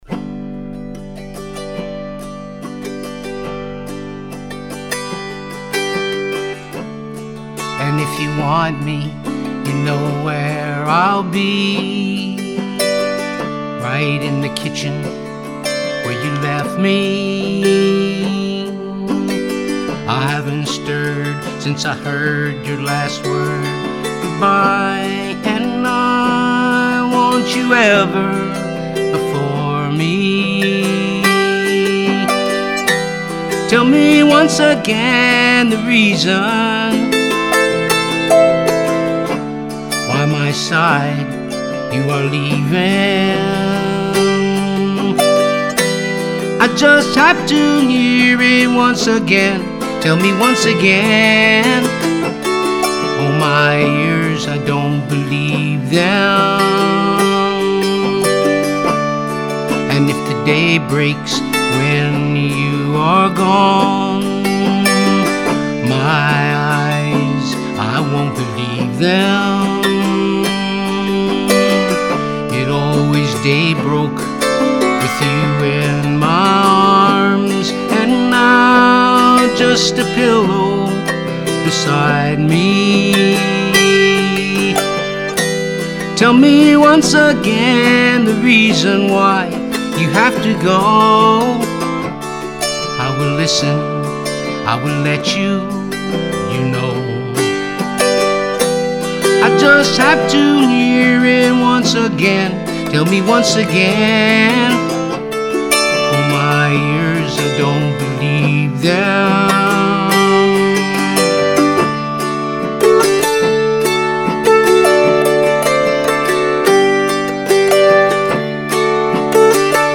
Recorded at Clinton Studios New York City